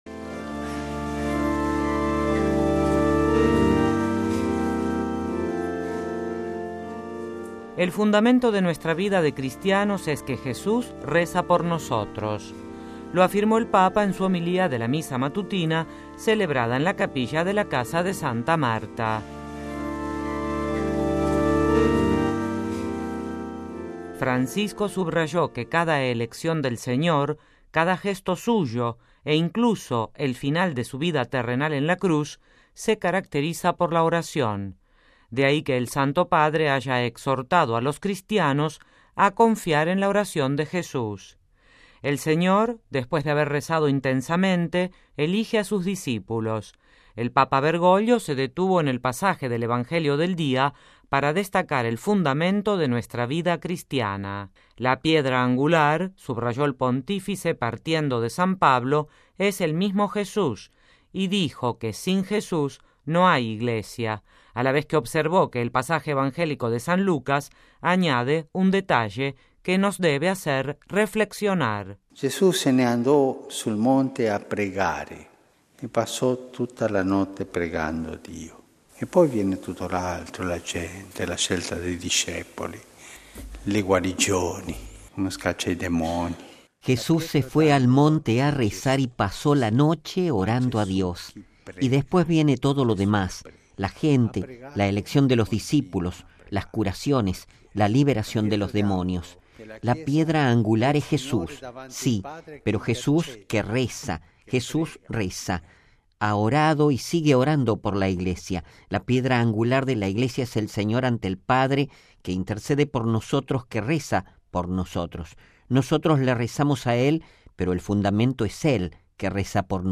Homilía del Papa: La piedra angular de la Iglesia es Jesús
(RV).- El fundamento de nuestra vida de cristianos es que Jesús reza por nosotros. Lo afirmó el Papa en su homilía de la misa matutina celebrada en la capilla de la Casa de Santa Marta.